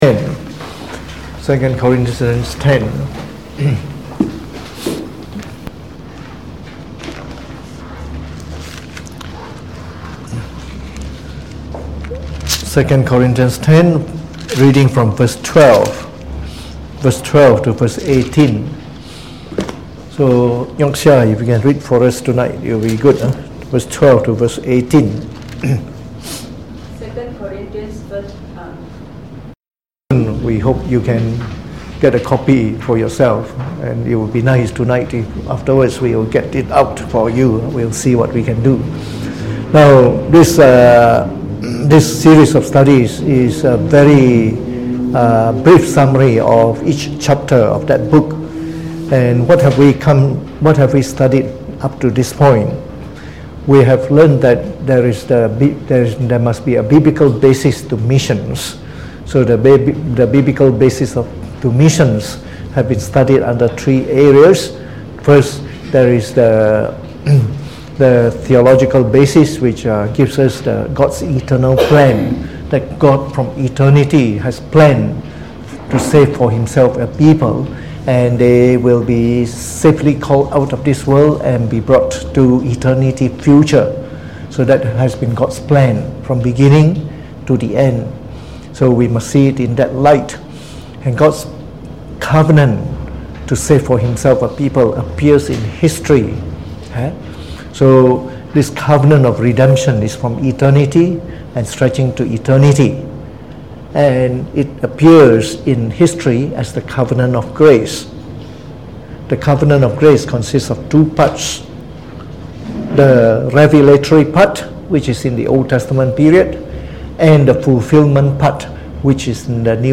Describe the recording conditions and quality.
Preached on the 30th of January 2019 during the Bible Study, from our series on Missions.